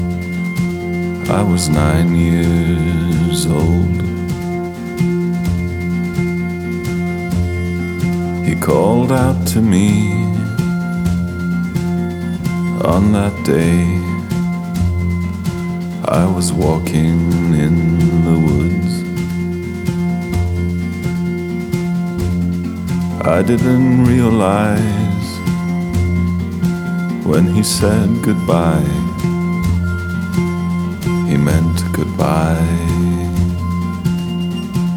Жанр: Альтернатива
Contemporary Folk